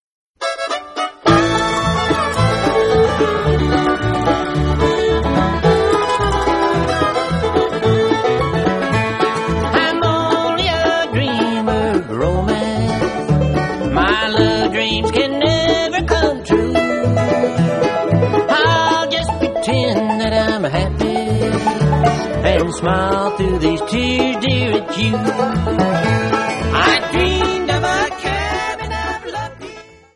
Bluegrass4.mp3